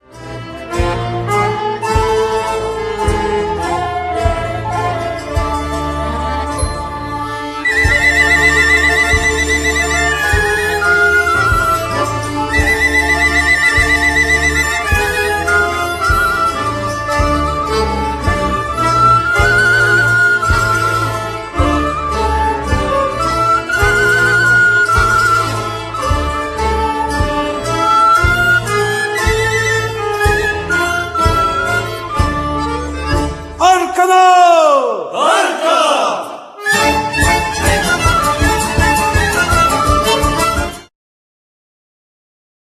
skrzypce, pasterskie instrumenty dęte, ¶piew
skrzypce, sopiłka, ¶piew
cymbały, koboz
bajan
bębny, instrumenty perkusyjne
mandola, lira korbowa, gitara, ¶piew, dutar, zongora
kontrabas